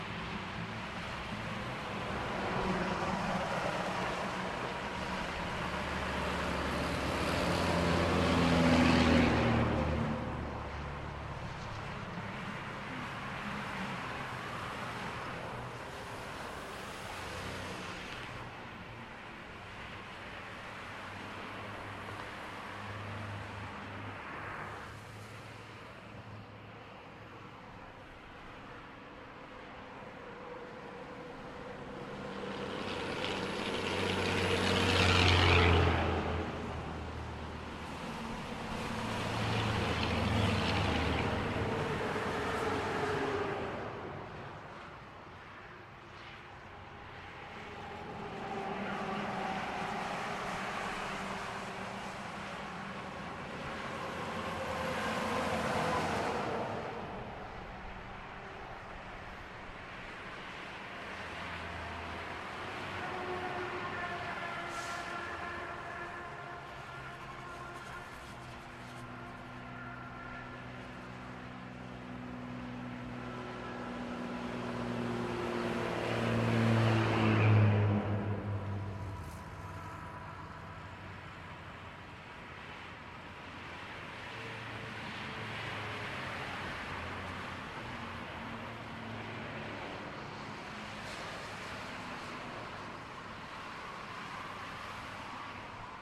声音设计第1天 " 6个停车场的氛围
描述：湿的停车场，相当大，位于高速公路附近。两个人在场地。周围有很多车。
Tag: 停车场 高速公路 湿